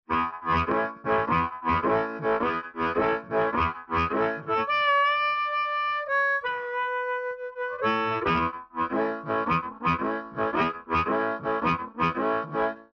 Harmonicas: Hohner Special 20 (Stock) in the key of A unless another key is specified
Microphone: Shure 520SL with mid 1950’s controlled magnetic element
Harmonica microphone is connected directly to the TubeUlent
Mic: Rodes NT-5 Condenser Mic
A collection of various settings using harmonica
Clean
Sound Samples recorded at Waterfront Productions
Harp_Clean.mp3